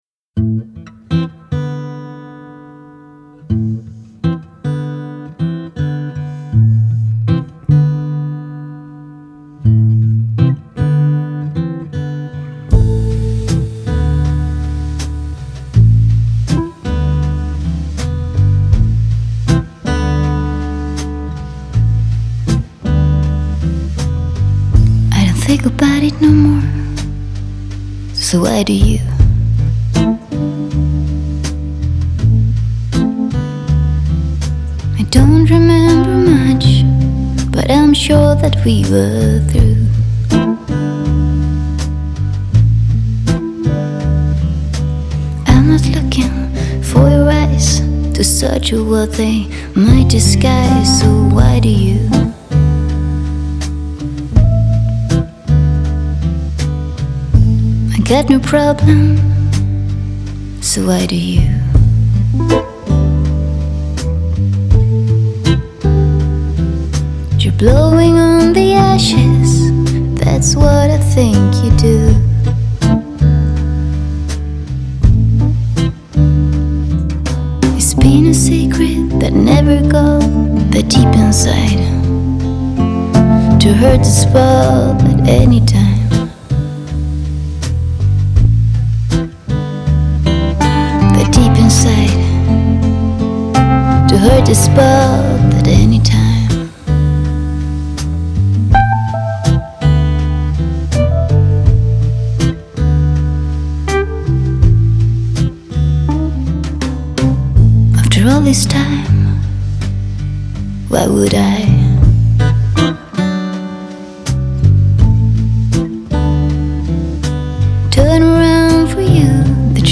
장르: Jazz, Pop
스타일: Vocal, Ballad